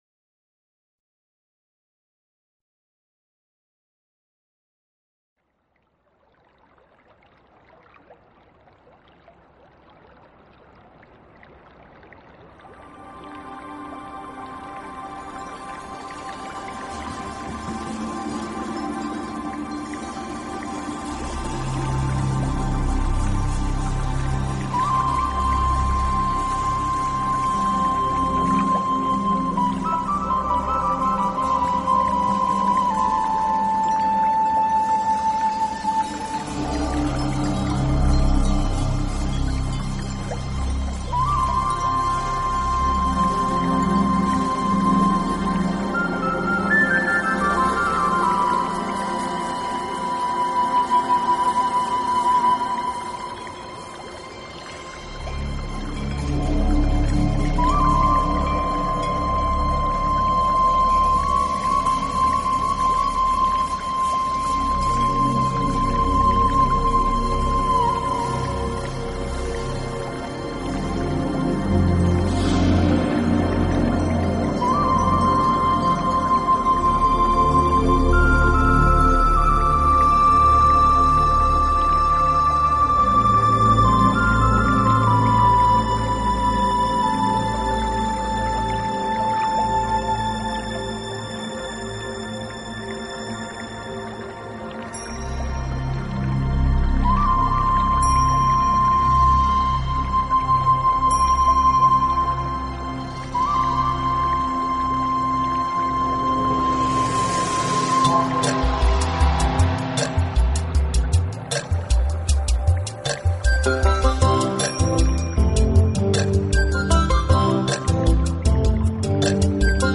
自然聲響與音樂的完美對話
海浪、流水、鳥鳴，風吹過樹葉，雨打在屋頂，
大自然的原始採樣加上改編的著名樂曲合成了天籟之音。